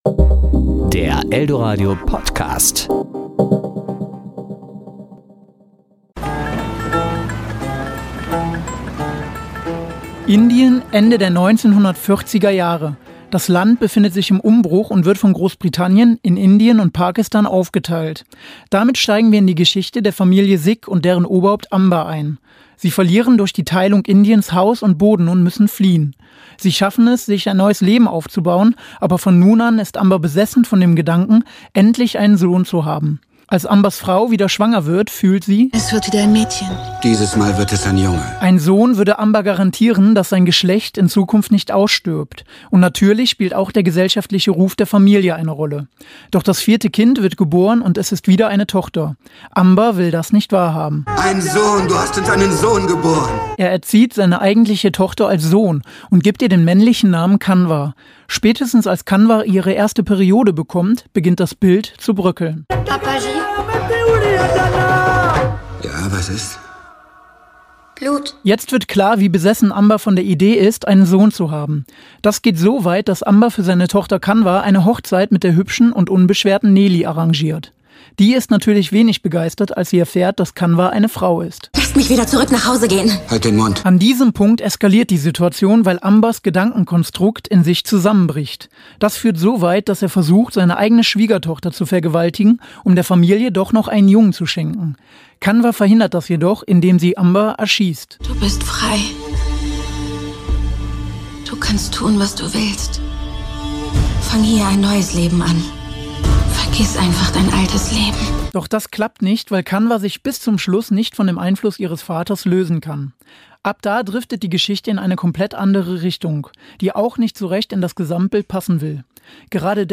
Serie: Rezensionen